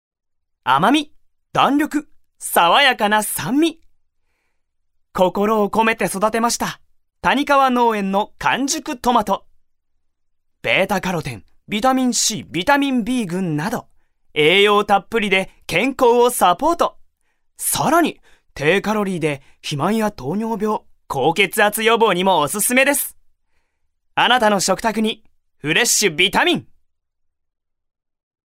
所属：男性タレント
ナレーション４